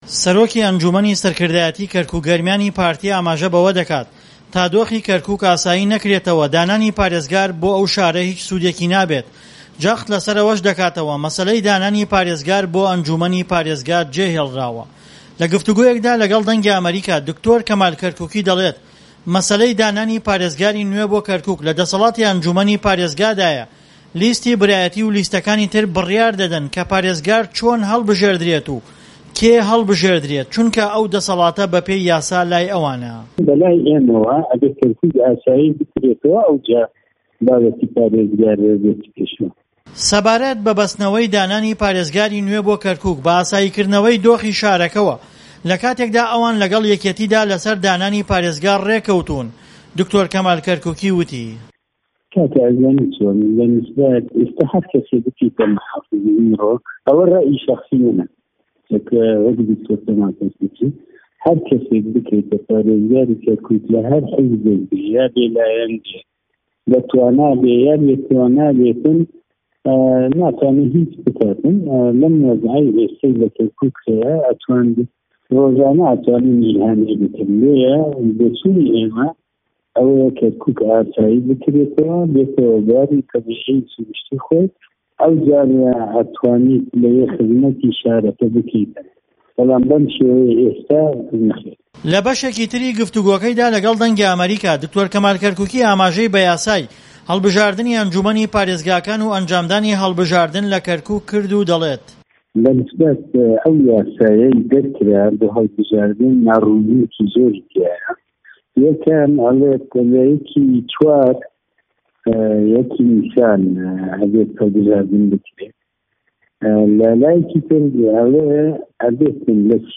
Kerem kin guh bidin vê raportê de ku tê de Kerkûkî ser jimareke mijarên girîng bersîvên pirsên me dide.